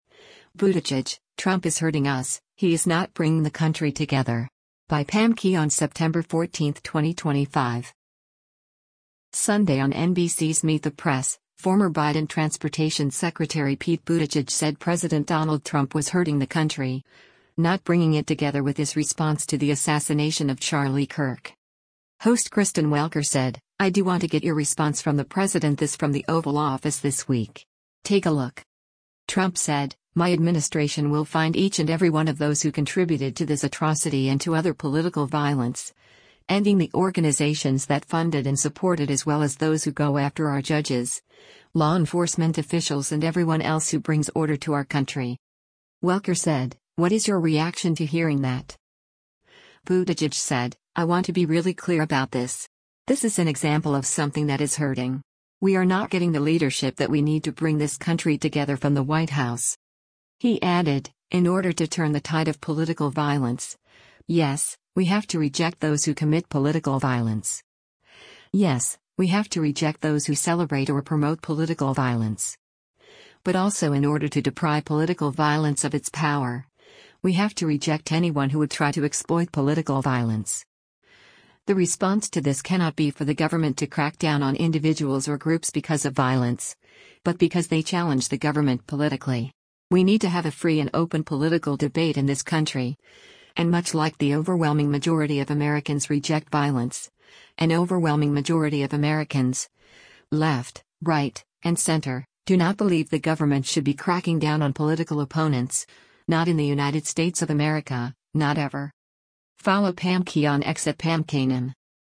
Sunday on NBC’s “Meet the Press,” former Biden Transportation Secretary Pete Buttigieg said President Donald Trump was “hurting” the country, not bringing it together with his response to the assassination of Charlie Kirk.